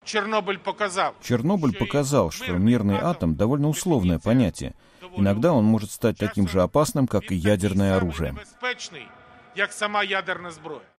Говорит президент Украины Петро Порошенко
--заявил Порошенко на памятной церемонии, которая состоялась на территории бывшей АЭС в Чернобыле.